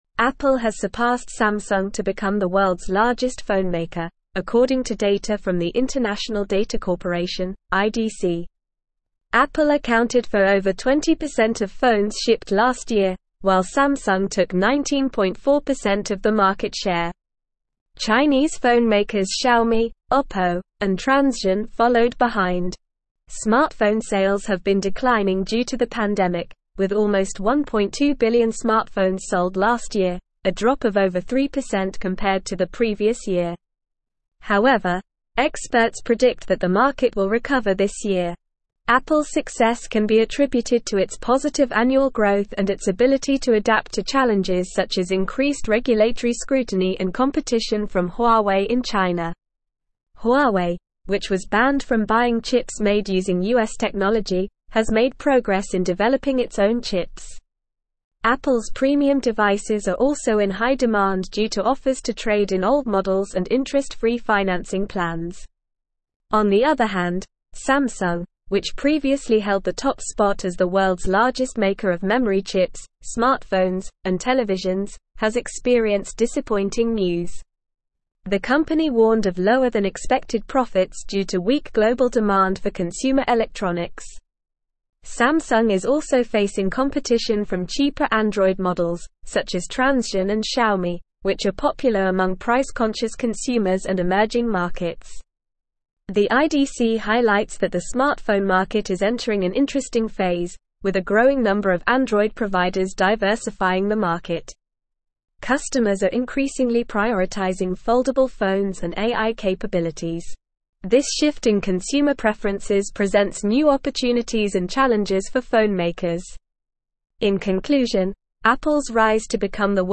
Normal
English-Newsroom-Advanced-NORMAL-Reading-Apple-Surpasses-Samsung-as-Worlds-Largest-Phonemaker.mp3